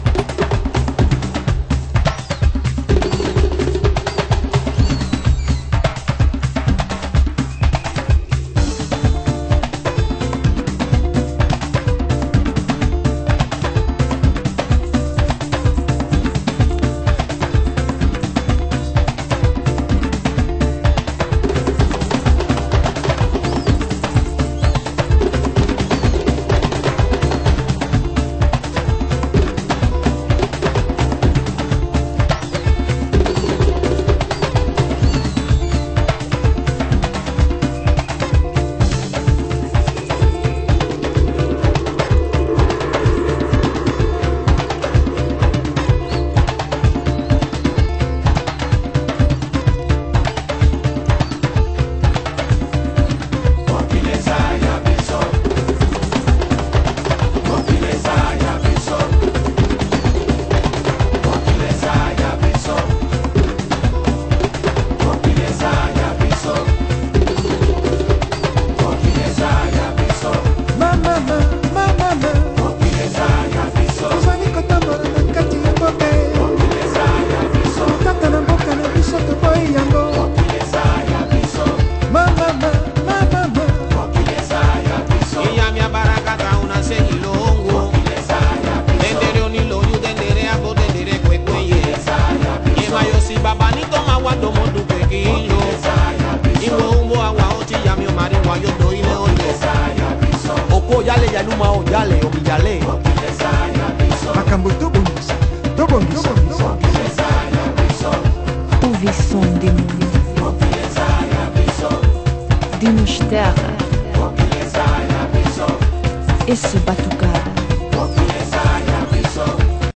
盤質：B面中盤に小さなんノイズ